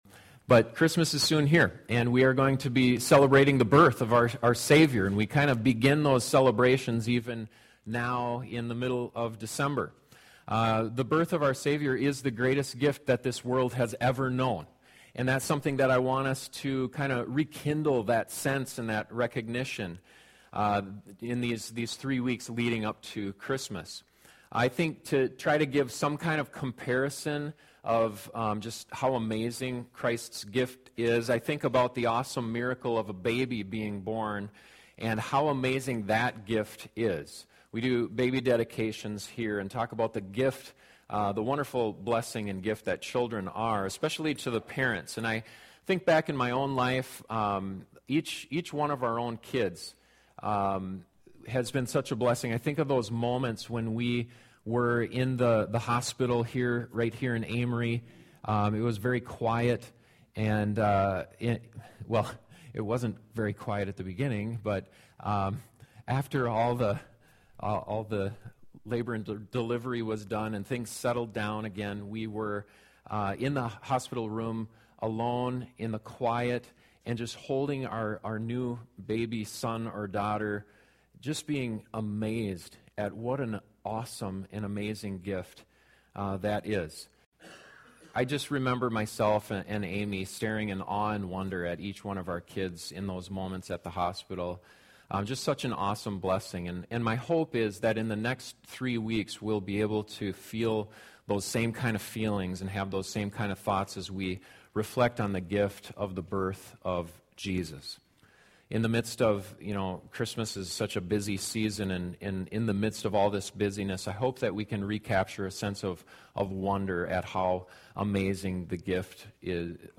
And one of the things that makes the gift of Jesus so spectacular is that he wasn’t given to us when we were God’s friends, he was given when we were his enemies. This sermon reminds us of the desperate condition we were in so we can appreciate the gift of Jesus more fully.